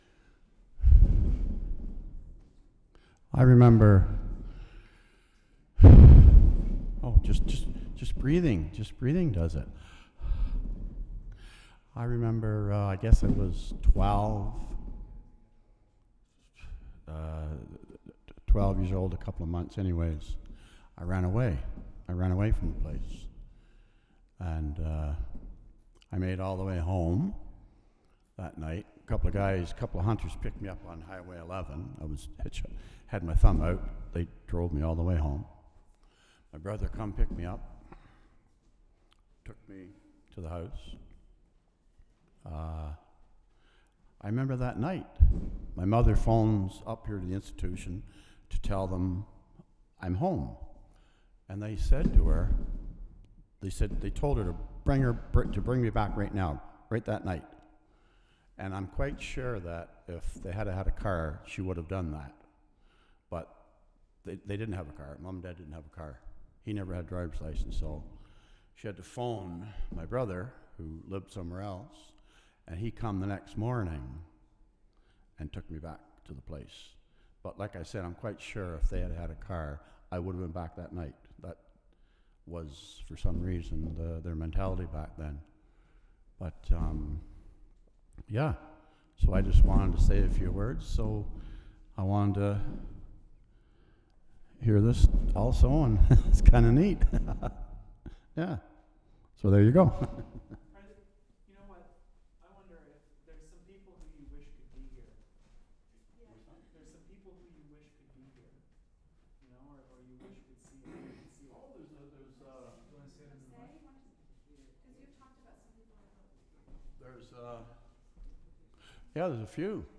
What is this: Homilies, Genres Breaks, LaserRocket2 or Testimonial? Testimonial